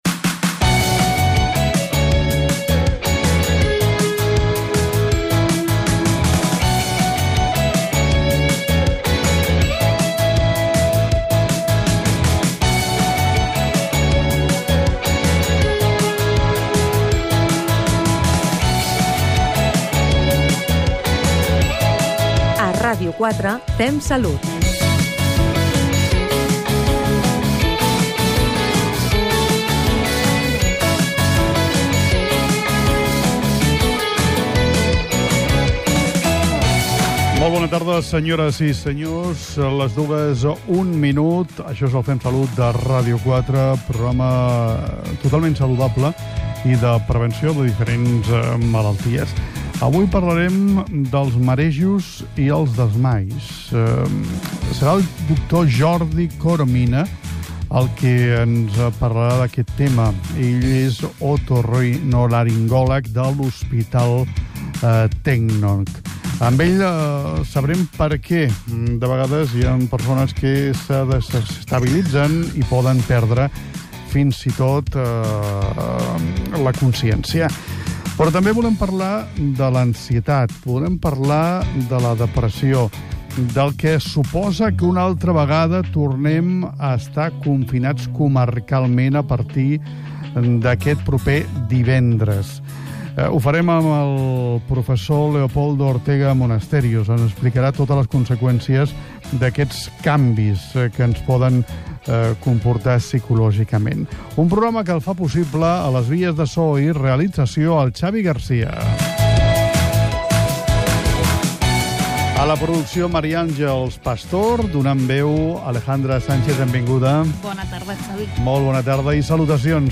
Careta del programa, sumari de continguts, dades de persones afectades per la pandèmia de la Covid-19 a Catalunya, promoció del programa "Metropoli", indicatiu del programa
Divulgació